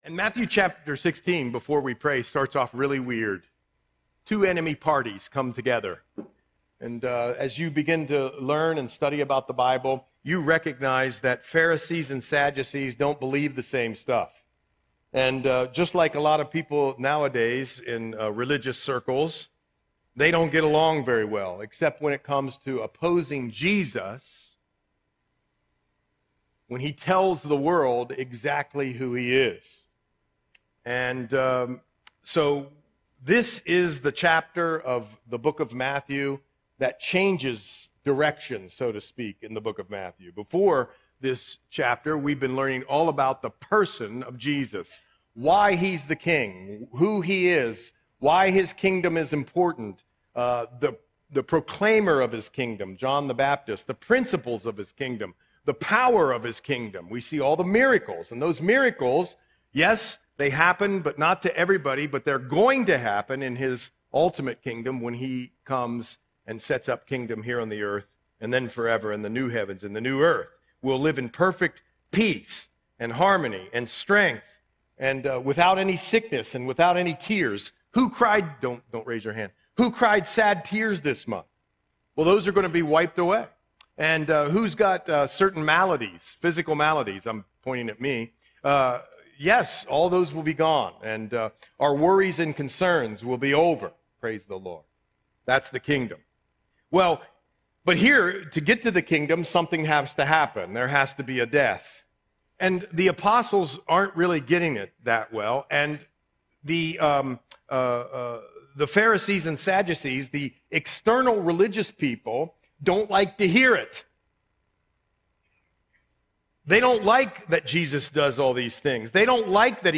Audio Sermon - March 22, 2026